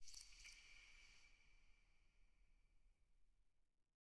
eyeblossom_close_long.ogg